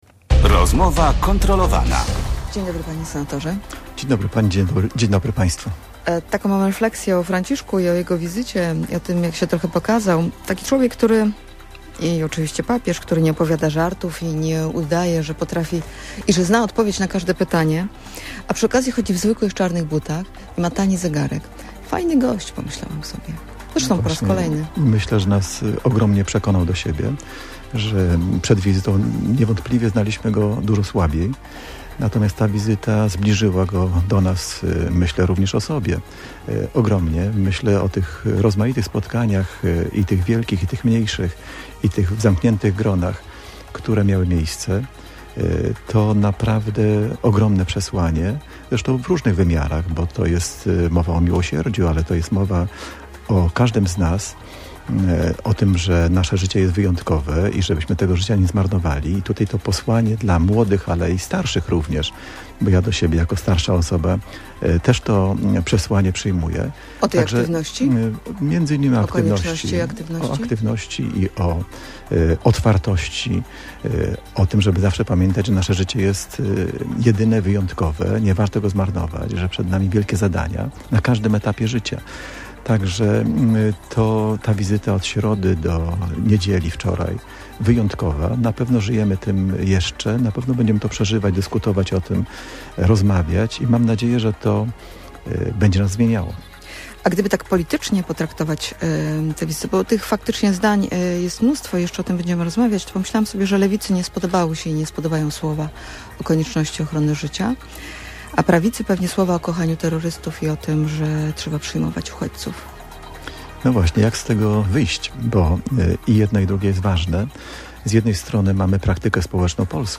Katolicki działacz i senator Prawa i Sprawiedliwości był gościem Rozmowy Kontrolowanej.